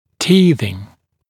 [‘tiːðɪŋ][‘ти:зин]прорезывание зубов (обычно имеется в виду прорезывание первых зубов в младенческом возрасте)